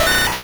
Cri de Sabelette dans Pokémon Rouge et Bleu.